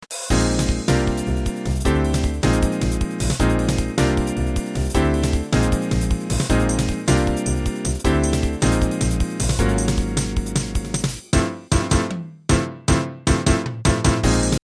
Microsoft GS Wavetable SW Synth（ソフト）[MP3ファイル]
RolandのGS音源を使用しているせいか、多少Rolandの音源の音色に依存する。